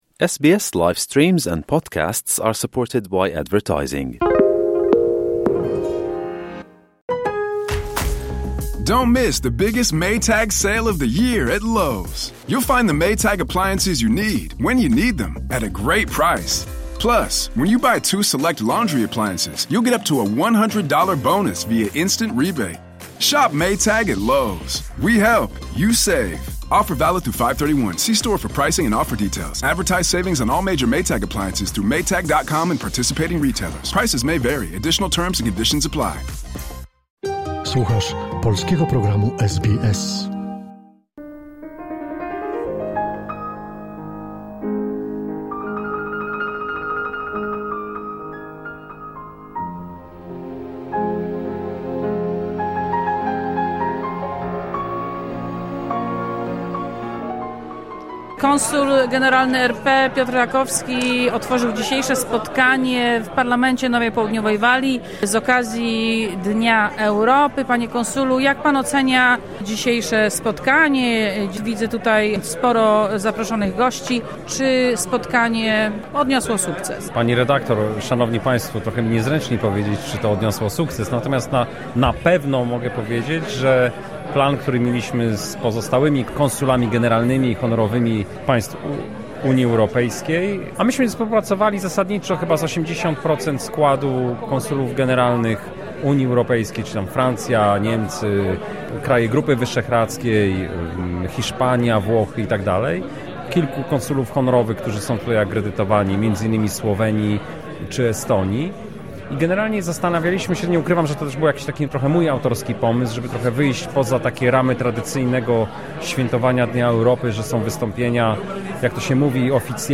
6 maja w siedzibie Parlamentu Nowej Południowej Walii odbyło się spotkanie z okazji Dnia Europy – Europe Day. Na spotkaniu zorganizowanym przez Konsulat RP w Sydney zebrali się lokalni australijscy politycy, w tym przewodniczący Izby Legislacyjnej Parlamentu NSW Ben Franklin, przedstawiciele korpusu dyplomatycznego krajów Europy oraz przedstawiciele wiodących lokalnych organizacji. W roli głównego mówcy gościnnie wystąpił znany australijski popularyzator nauki Karl Kruszelnicki.